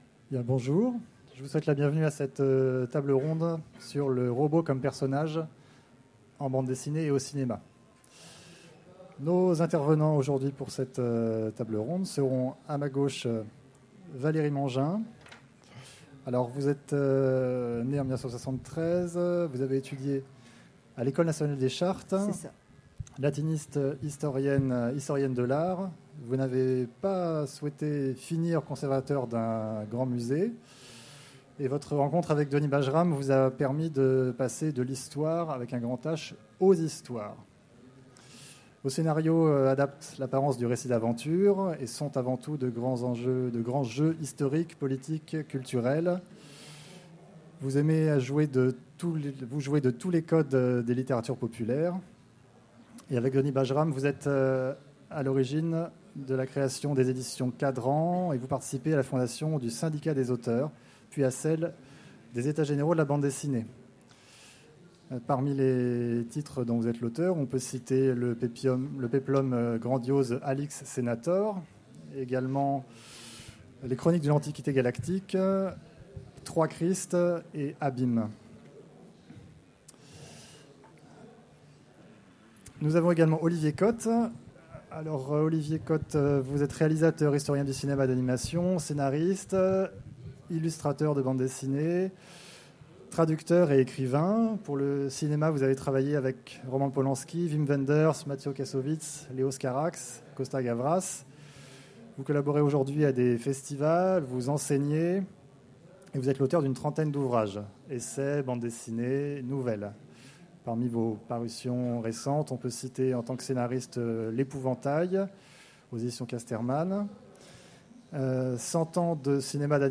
Utopiales 2016 : Conférence Le robot comme personnage au cinéma et en BD